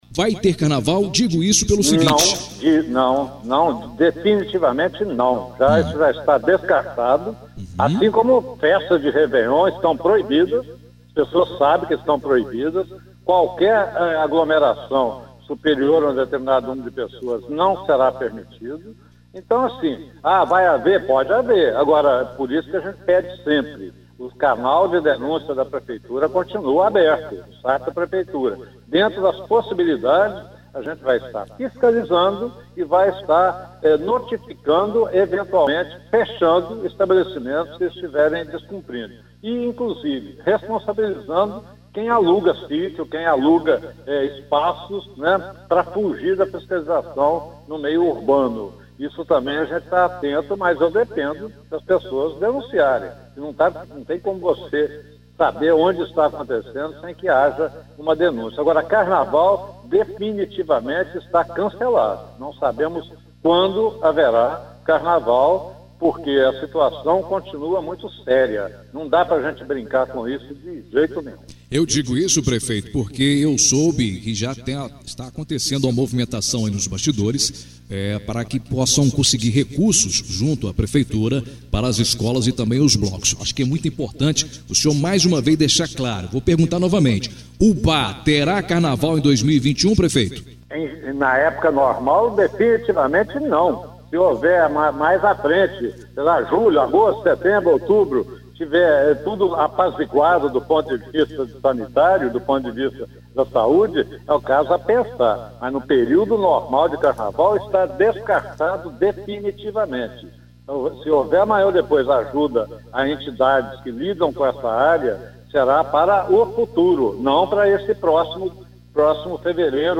O prefeito de Ubá Edson Teixeira Filho participou de uma entrevista no início da tarde desta sexta-feira(18/12) no Jornal em dia com a notícia da Rádio Educadora